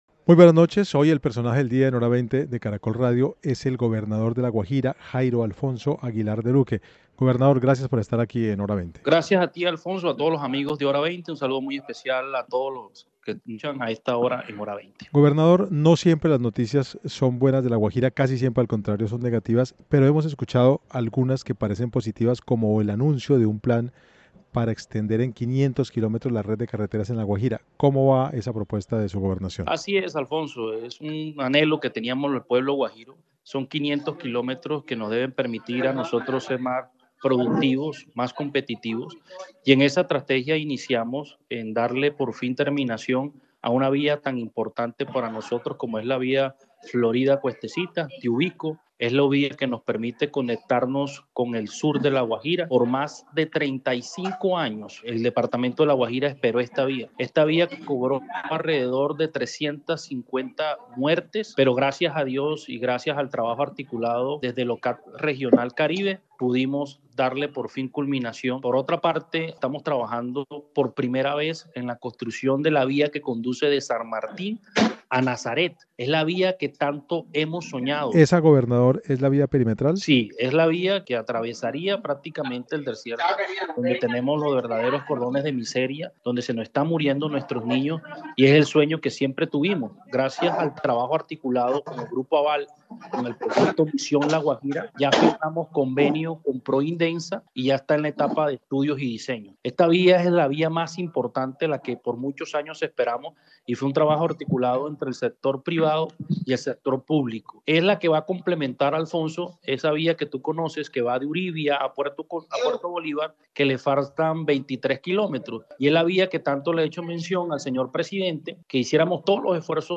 En diálogo con Hora20 de Caracol Radio, el gobernador de La Guajira, Jairo Alfonso Aguilar, señaló que los 500 kilómetros de vía permiten un departamento más competitivo y productivo, “en esa estrategia iniciamos con dar fin a una vía como la es Florida-Puestecita, la cual permite conectar el sur del departamento”.